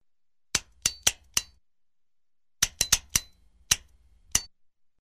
Звук ночника: щелчки выключателя несколько раз